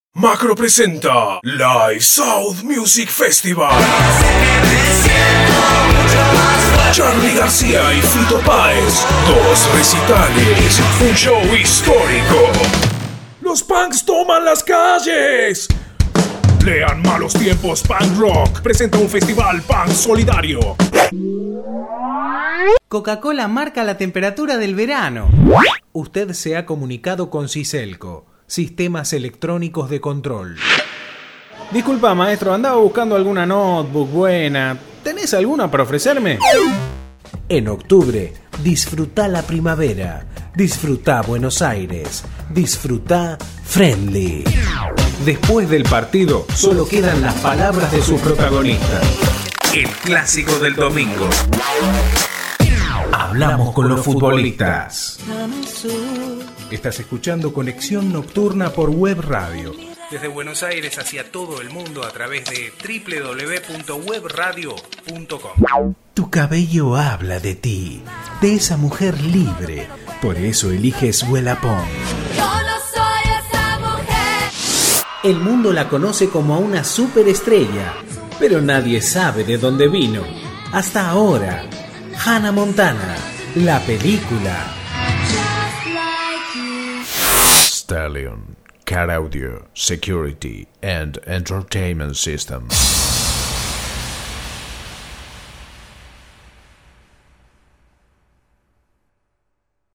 Masculino
Espanhol - Argentina
Mi voz también fue incluída en videos corporativos, publicidades virales, cortos documentales y artísticos, preatendedores, etc. Para tu comodidad dispongo de estudio propio.